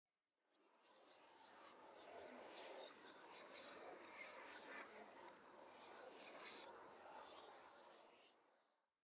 Minecraft Version Minecraft Version snapshot Latest Release | Latest Snapshot snapshot / assets / minecraft / sounds / ambient / nether / soulsand_valley / whisper1.ogg Compare With Compare With Latest Release | Latest Snapshot
whisper1.ogg